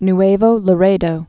(n-āvō lə-rādō, nwĕvō lä-rĕdō)